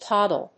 音節tod・dle 発音記号・読み方
/tάdl(米国英語), tˈɔdl(英国英語)/